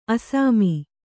♪ asāmi